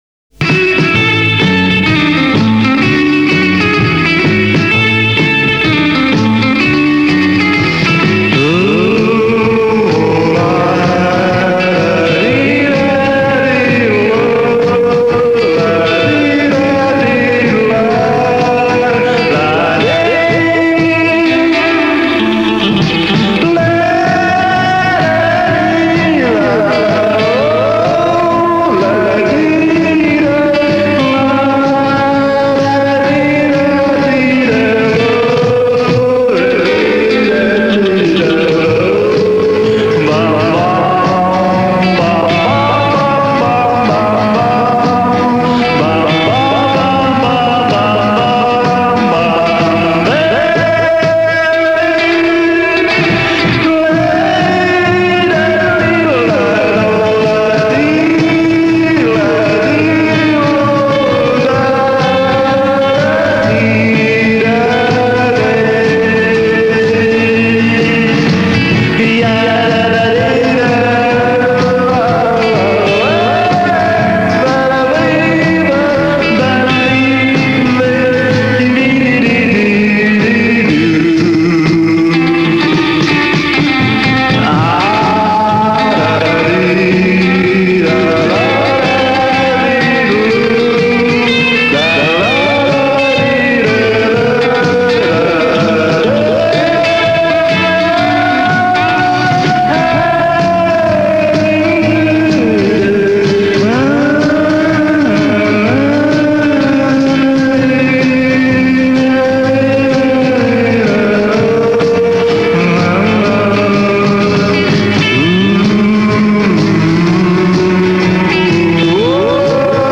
over backing track
没有歌词，颠覆性的演唱